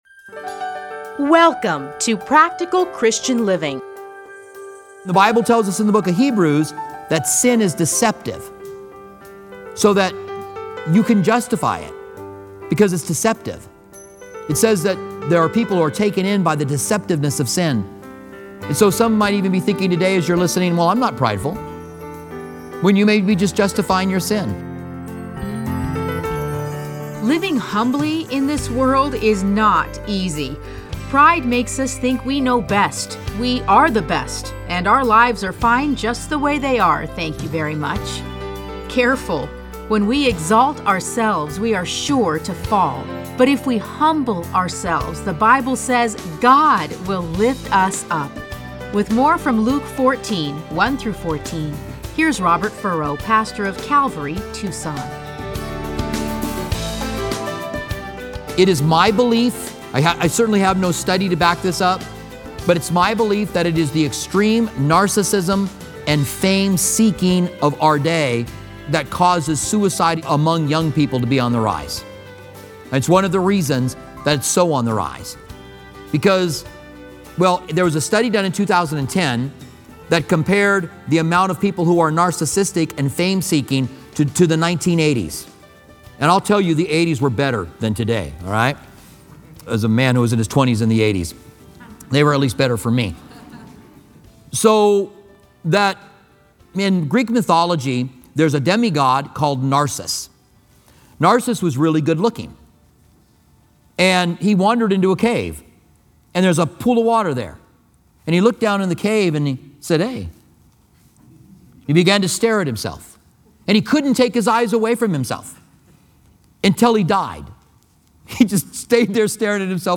Listen to a teaching from Luke 14:1-14.